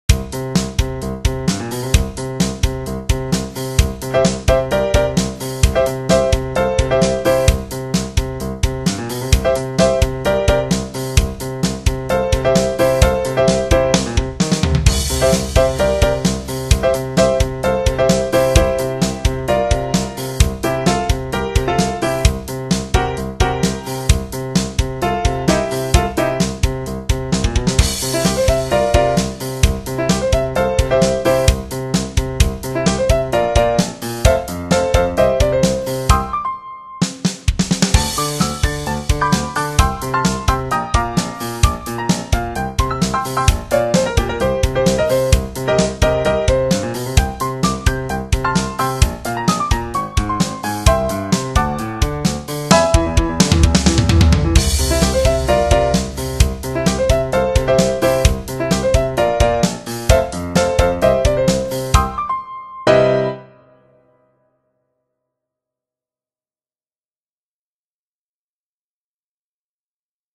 در پایان می توانید نمونه صوتی قطعه ها که با سمپل تهیه شده اند به همراه تعدادی از پی دی اف های آماده شده را در زیر این مطلب دانلود نمایید.
۱۰ – میکسولیدین – کاربردی در ایجاد موسیقی راک
10-Mixolydian-in-rock.wma